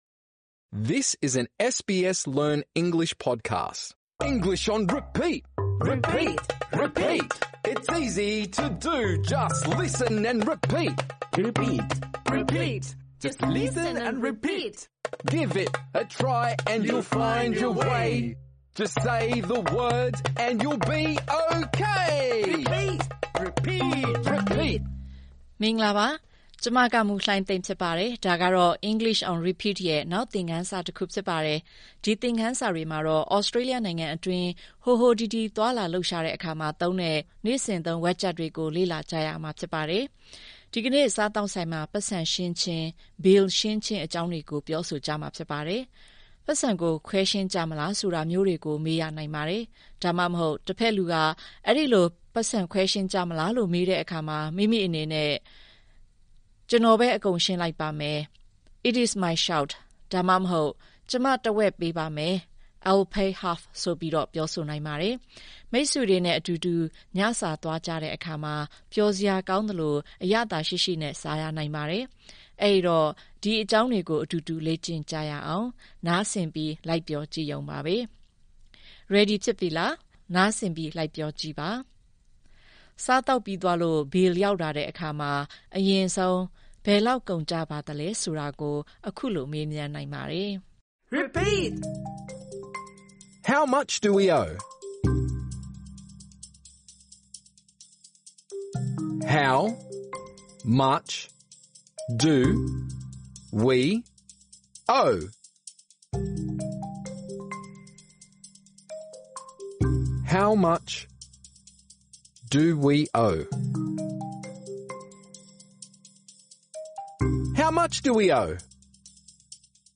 ဒီသင်ခန်းစာတွေဟာ လွယ်ကူပြီး အခြေခံကျတဲ့ သင်ခန်းစာတွေ ဖြစ်ပါတယ်။ ဒီသင်ခန်းစာမှာတော့ အောက်ပါဝါကျတွေရဲ့ အသံထွက်ကို လေ့ကျင့်ကြမှာပါ။